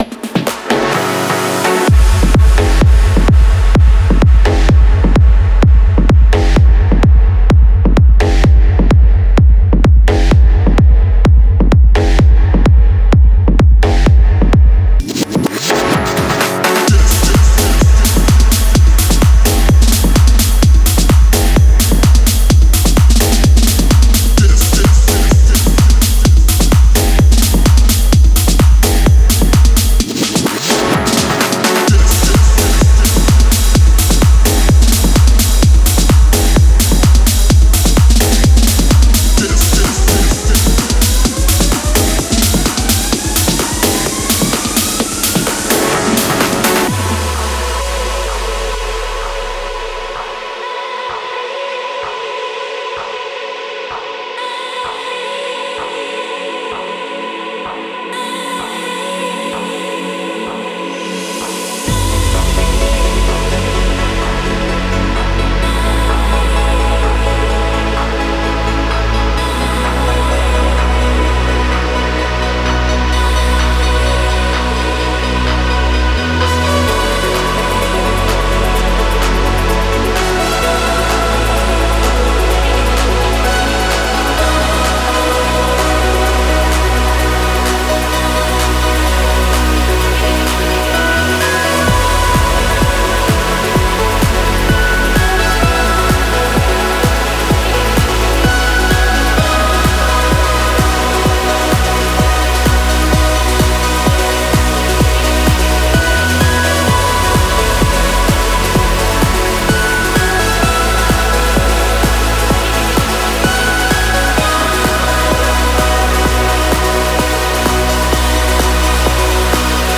• Категория:Uplifting Trance